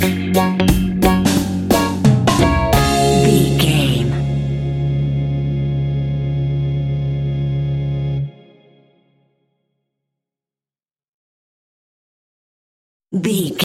A groovy and funky piece of classic reggae music.
Aeolian/Minor
D♭
laid back
chilled
off beat
drums
skank guitar
hammond organ
percussion
horns